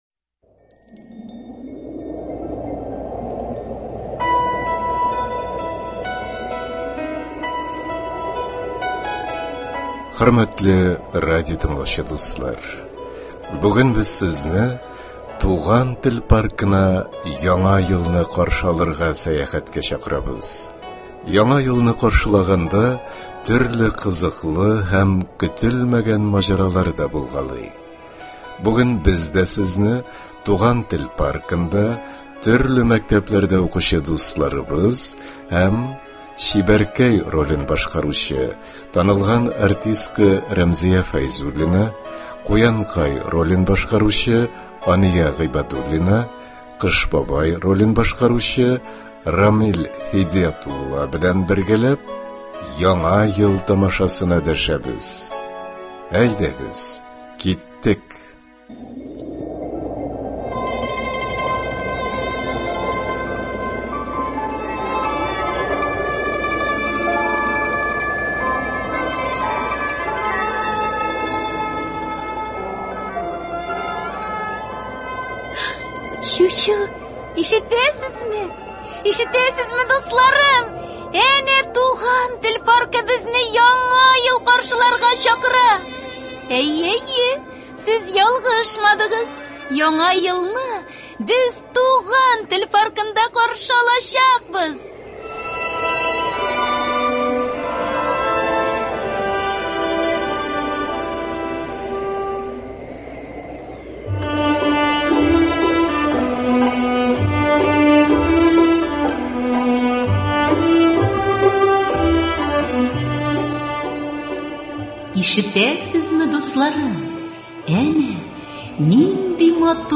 Балалар өчен радиотамаша (31.12.20)